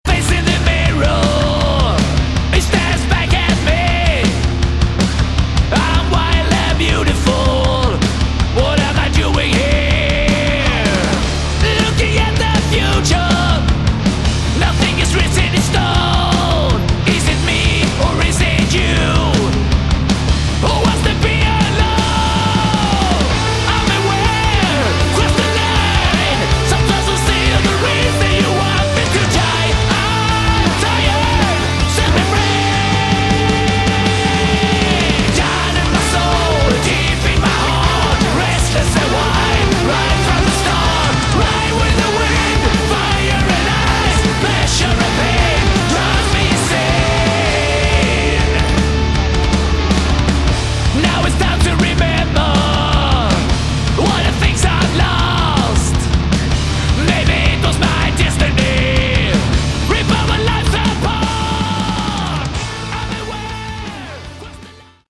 Excellent sleazy hard rock!
This is good old-school dirty sleazy hard rock.